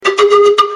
Pan flutes soundbank 3